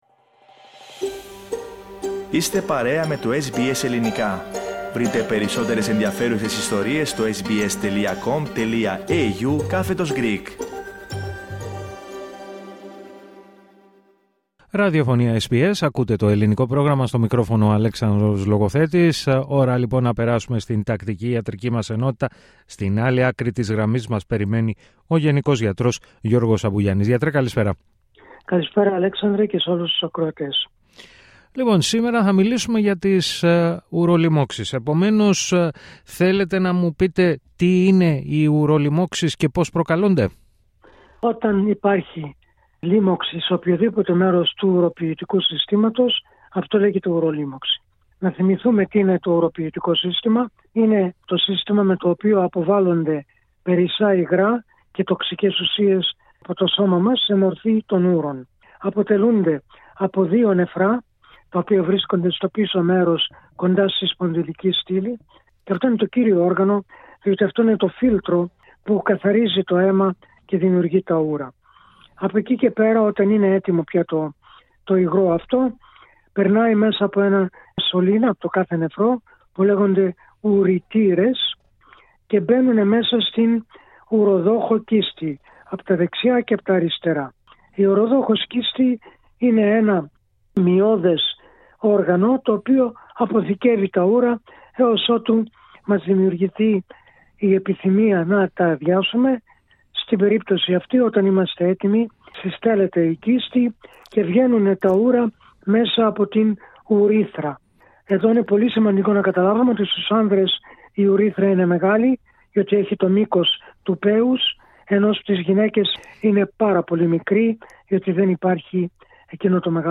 Ο γενικός γιατρός